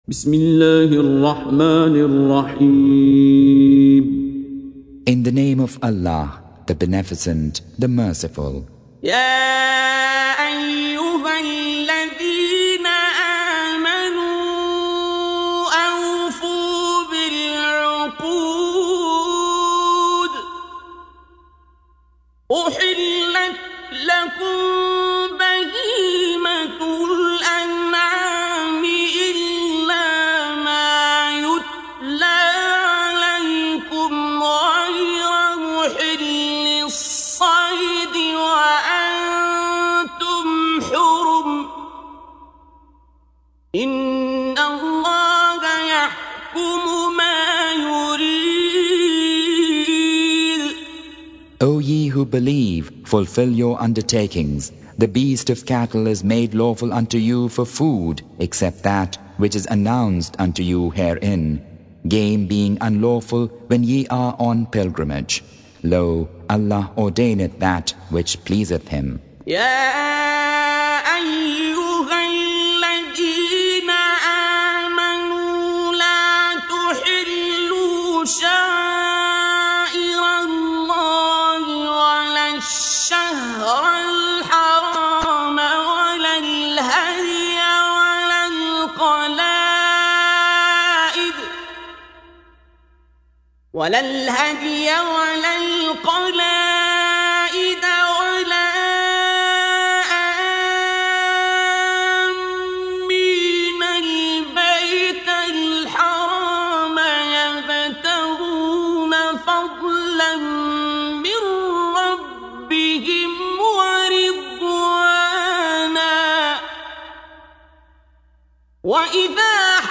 Surah Repeating تكرار السورة Download Surah حمّل السورة Reciting Mutarjamah Translation Audio for 5.